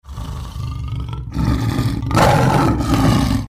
лев
рычание